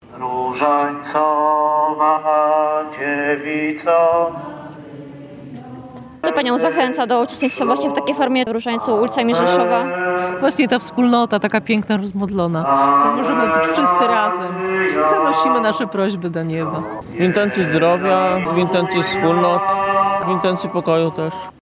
Ponad 400 osób wzięło udział w corocznej Rzeszowskiej Procesji Różańcowej.
Zgromadzeni, jak mówili, przyszli z różnymi intencjami.
17-1-lok.-rozaniec-ulicami-Rzeszowa.mp3